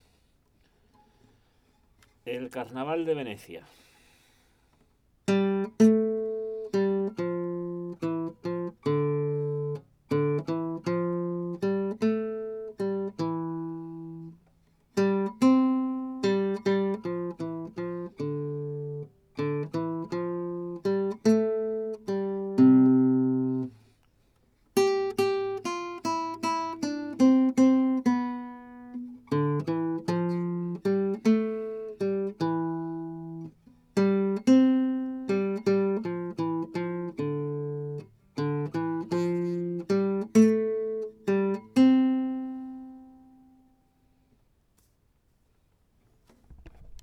lección en audio Carnaval